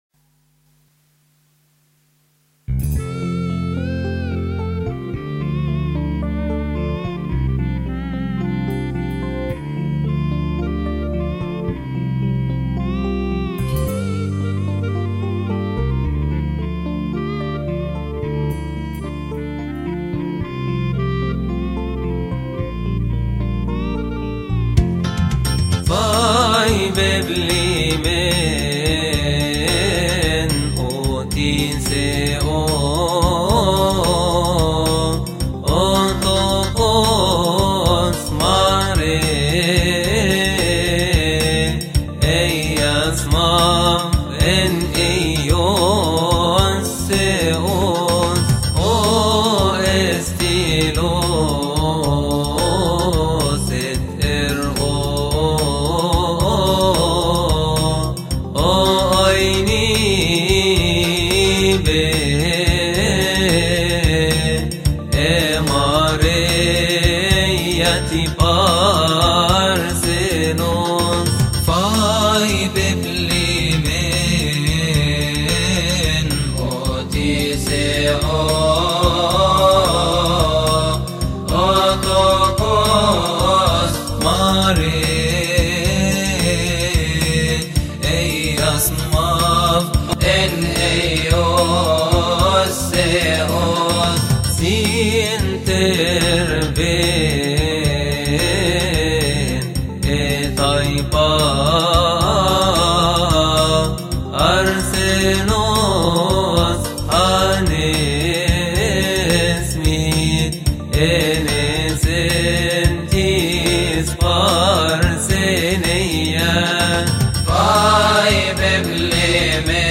لحن فاي بي إبليمين.mp3